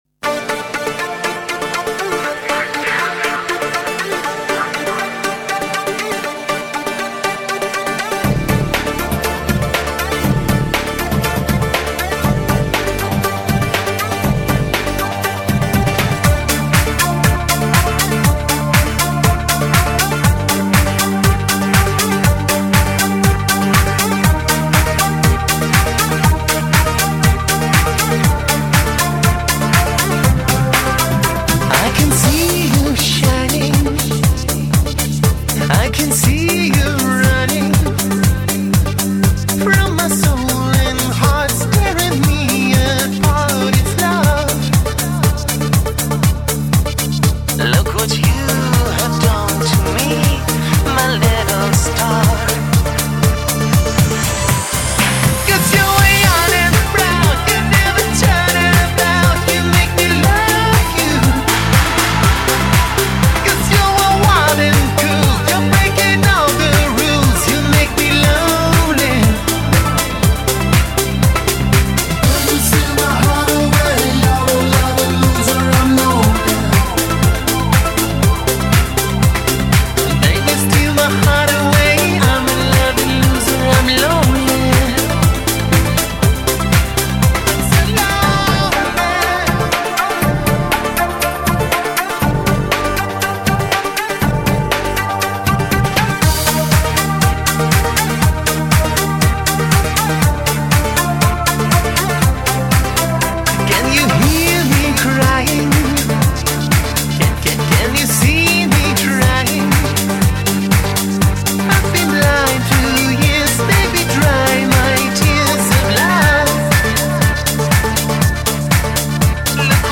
вокал
клавишные, бэк-вокал
Стиль исполнения колеблется от синти-попа до поп-рока.